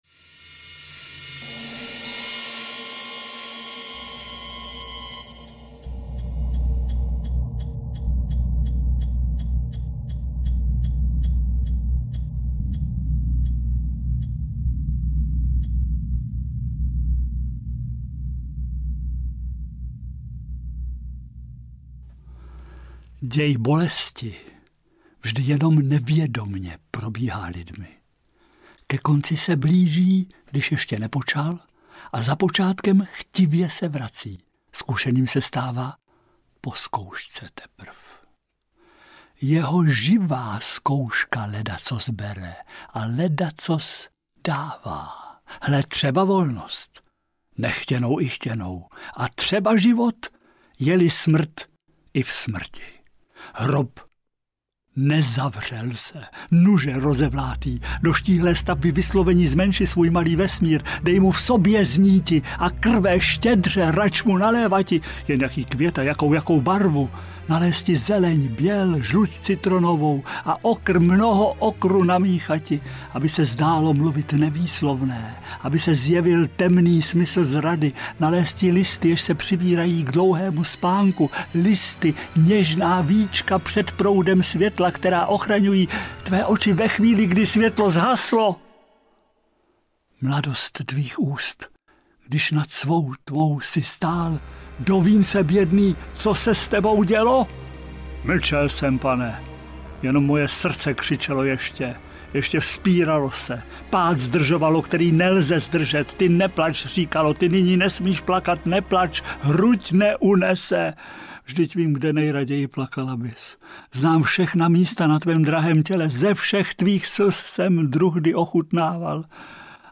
Elegie audiokniha
Ukázka z knihy